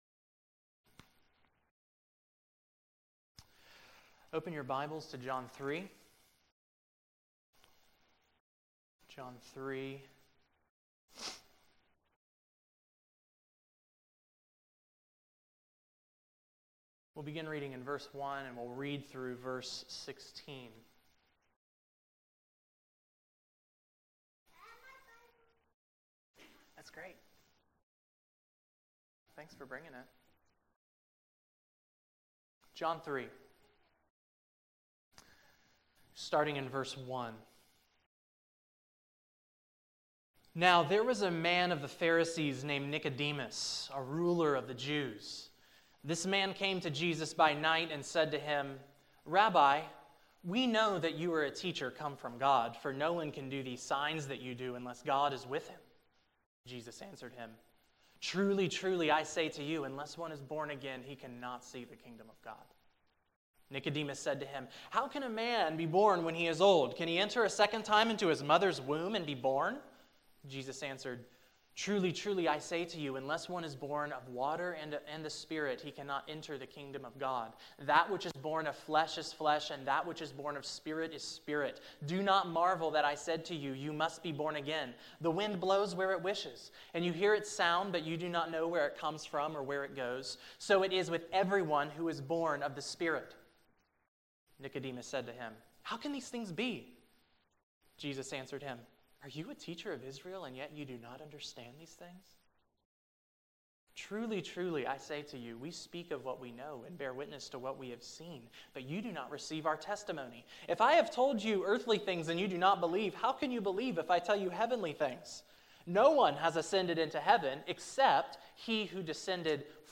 July 3, 2015 Morning Worship | Vine Street Baptist Church